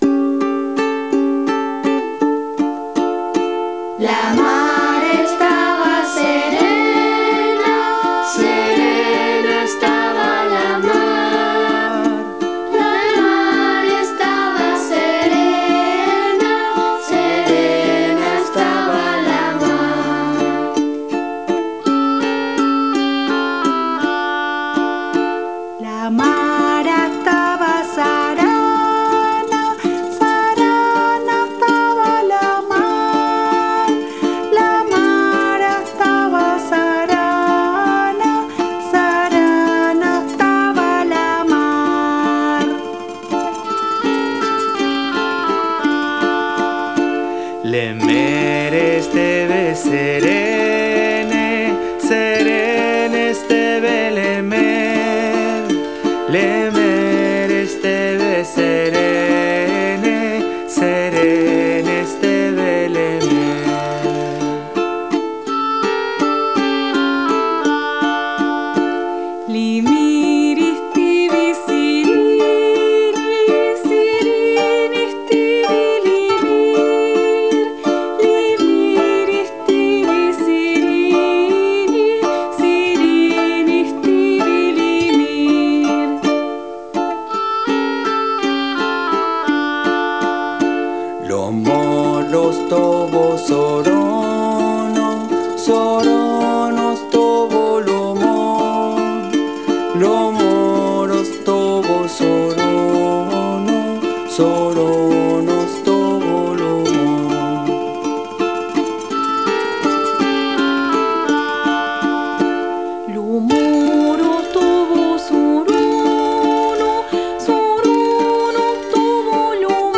Canción para jugar con las vocales